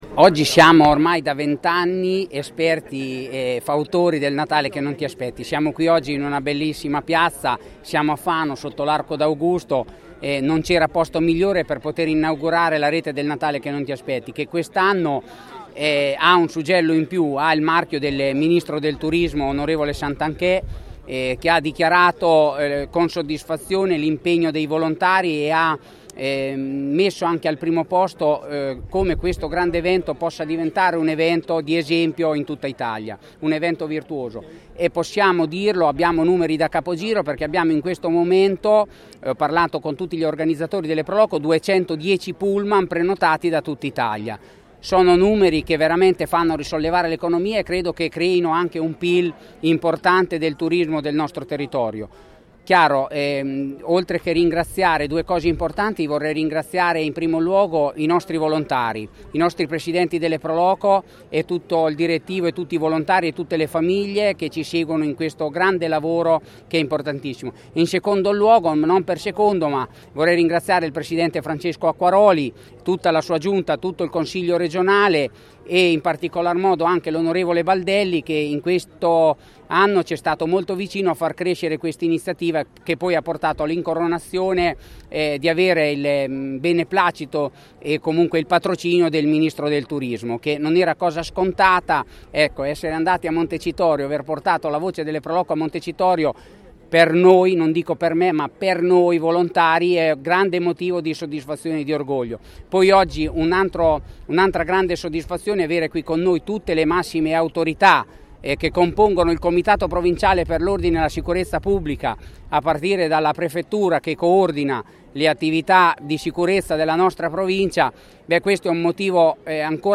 Cerimonia in grande stile, al Pincio di Fano, per il taglio del nastro che sancisce la partenza del “Natale Che Non Ti Aspetti”, la rete degli eventi di Natale più estesa d’Italia. Ventidue tra città e borghi trasformati in vere e proprie cartoline di Natale tutte da vivere.